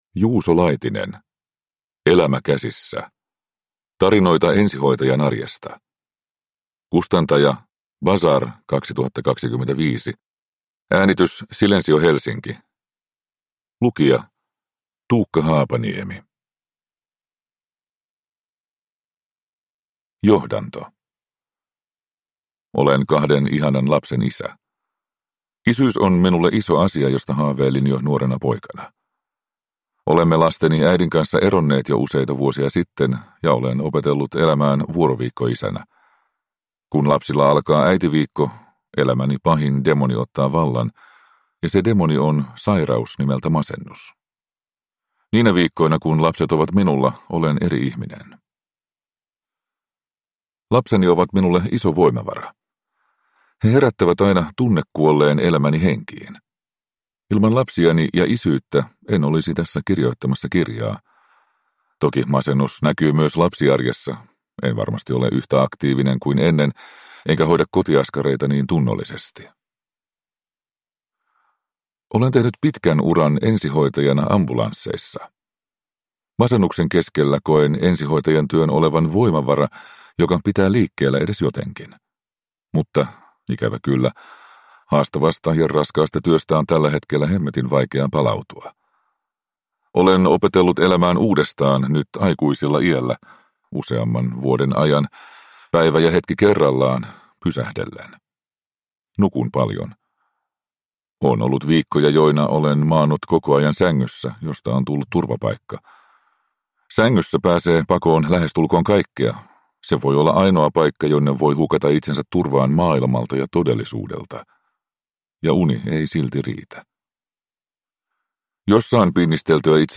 Elämä käsissä – Ljudbok